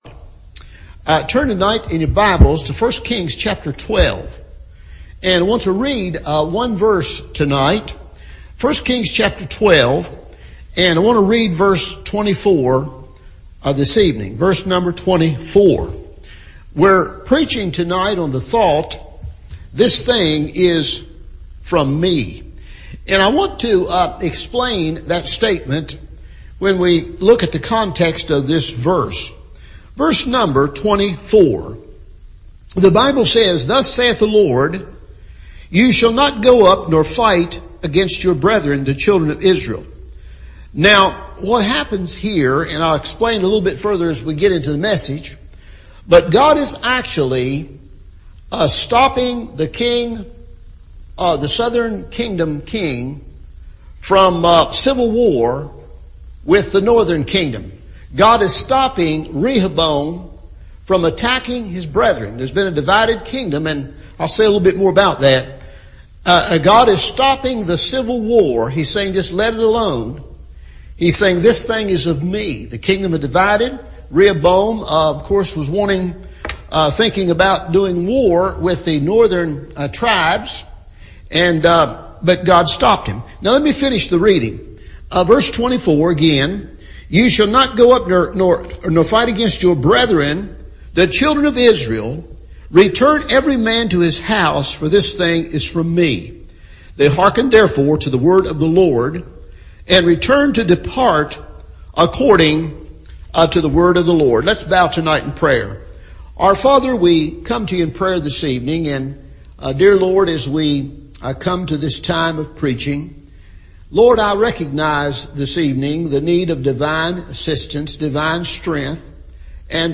This Thing is From Me – Evening Service